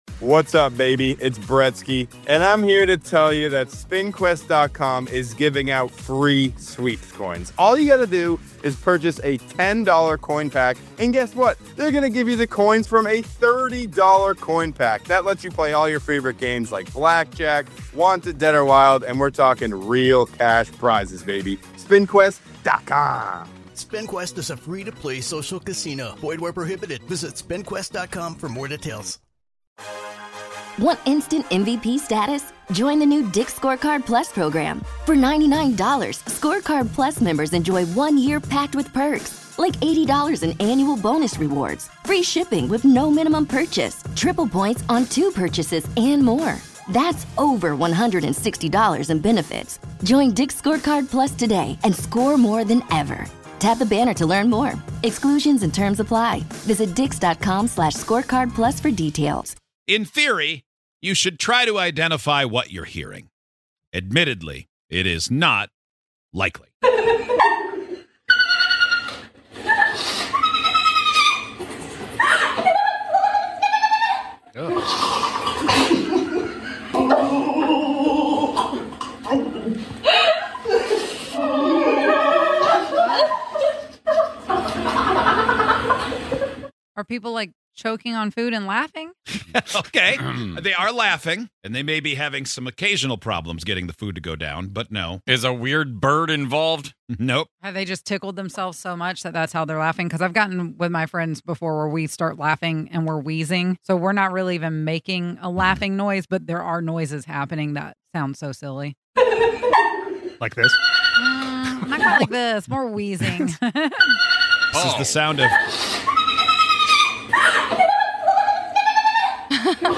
While we can make out some laughter here, we're not sure about the rest.